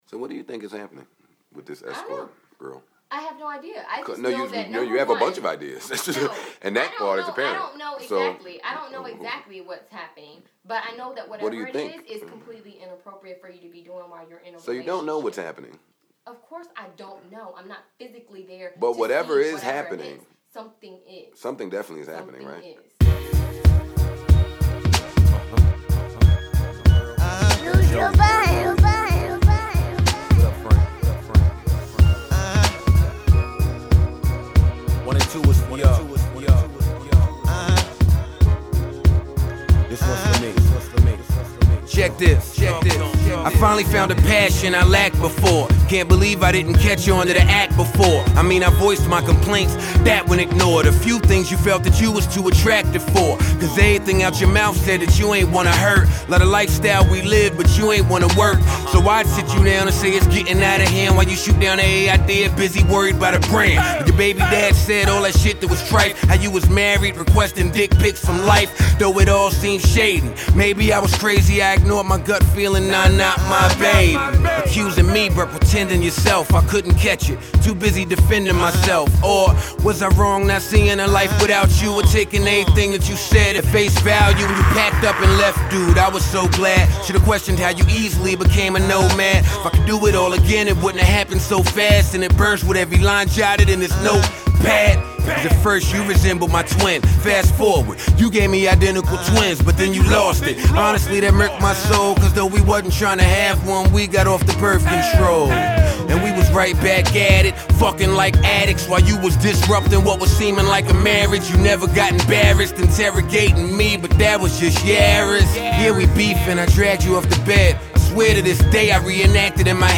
Gone are the Sade samples from parts one and two.